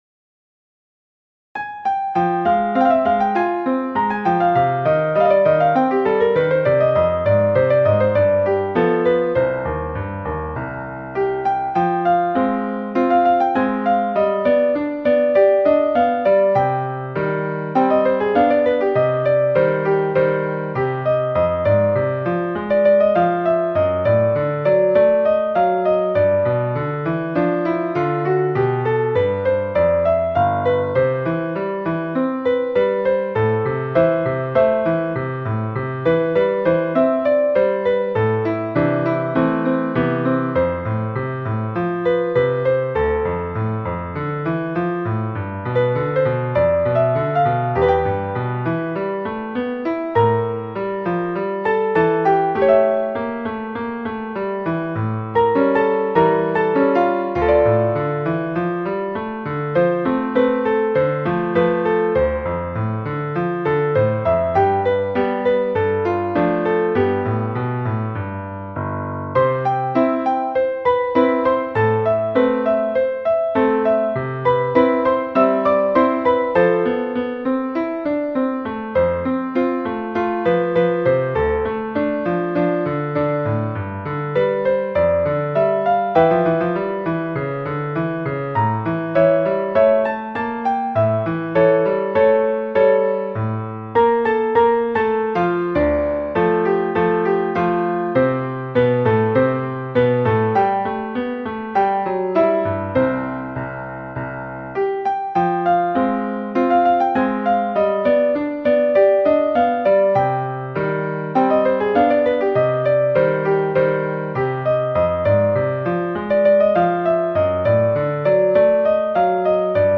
Partitura para piano / Piano score (pdf)
Escuchar partitura / Listen score (MP3) (Robot)